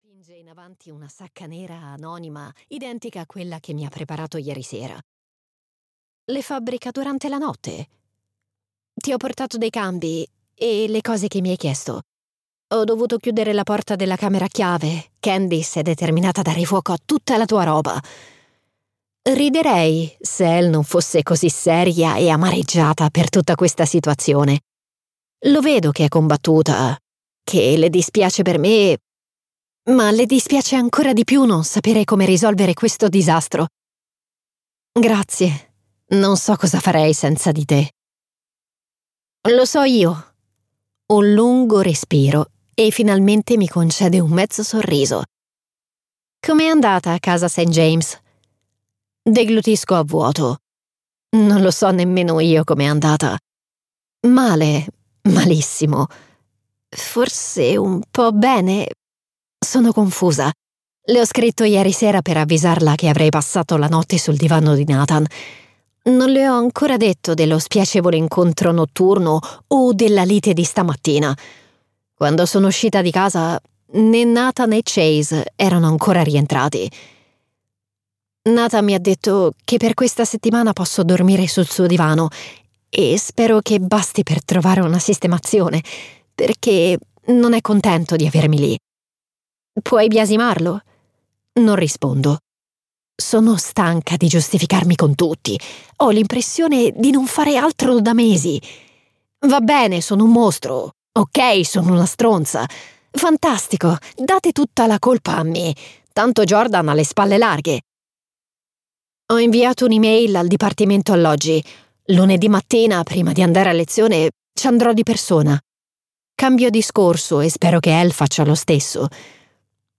"After Midnight" di Valentina Ferraro - Audiolibro digitale - AUDIOLIBRI LIQUIDI - Il Libraio